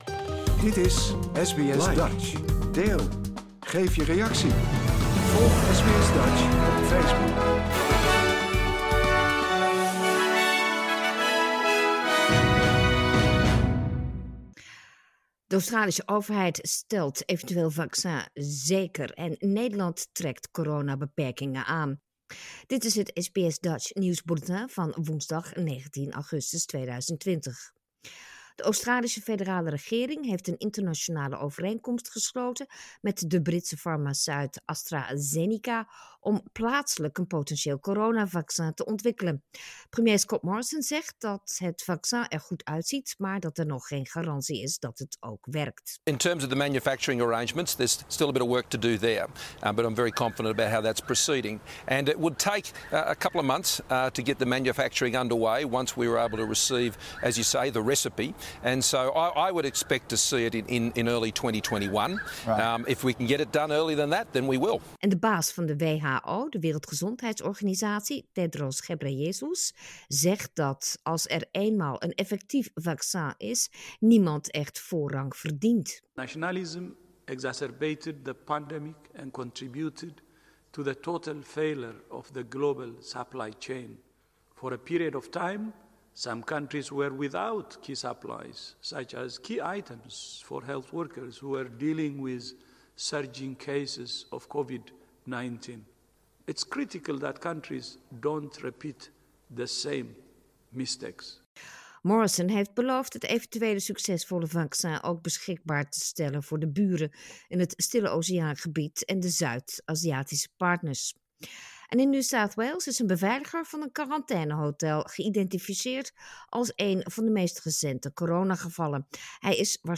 Nederlands/Australisch SBS Dutch nieuws bulletin woensdag 19 augustus 2020